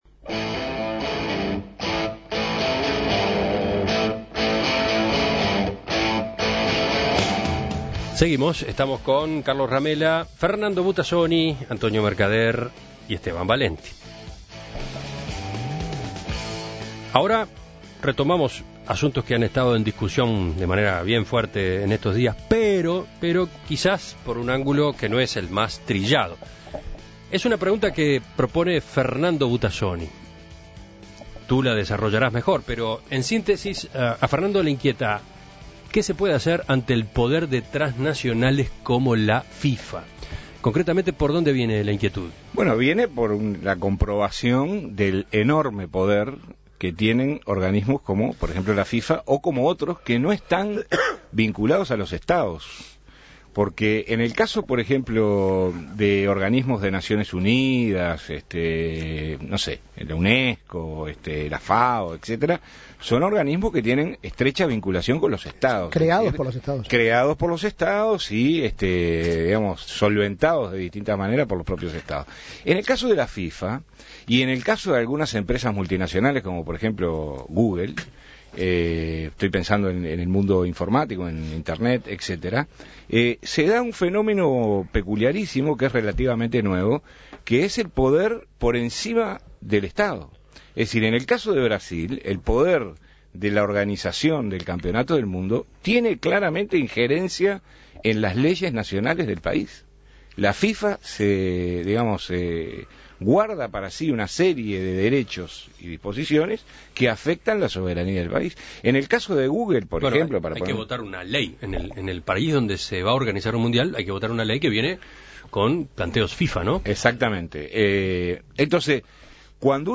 La Tertulia ¿Qué hacer ante el poder de transnacionales como la FIFA?